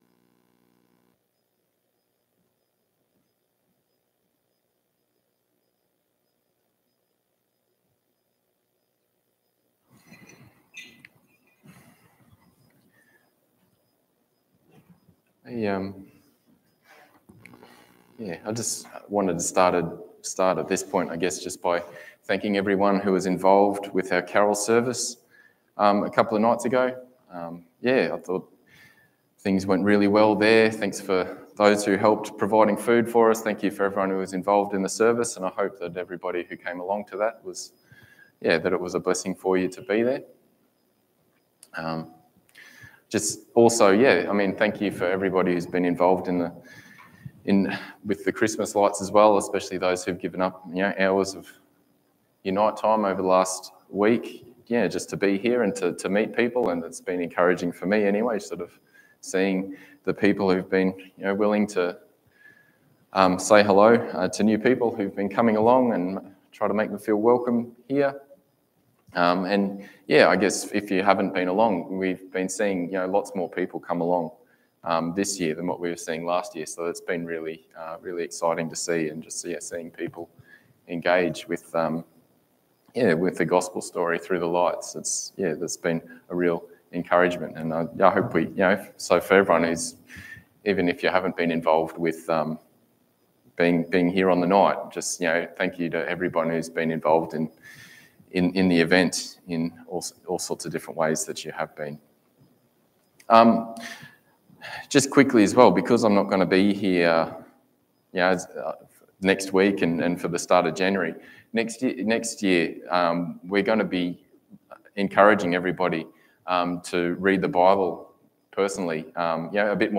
Series: How Jesus Fulfils Our Deepest Longings Service Type: Sunday Morning